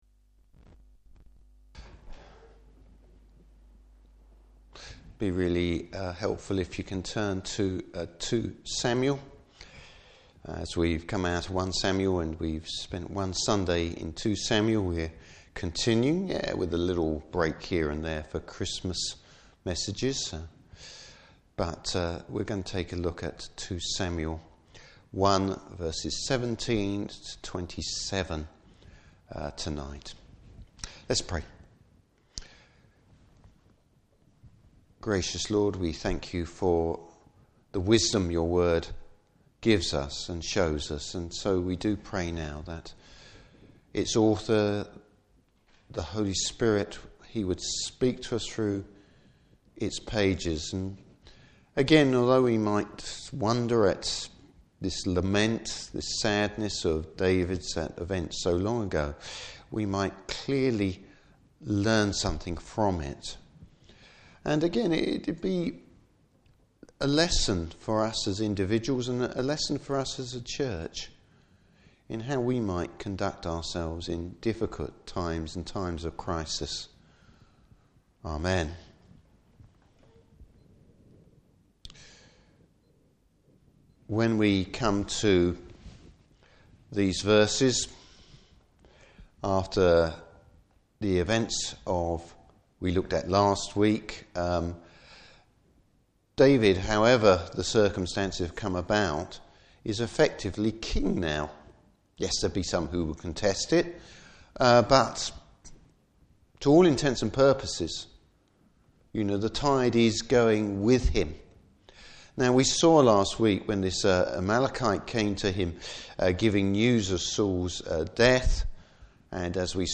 Service Type: Evening Service David’s sorrow at Saul and Jonathan’s death.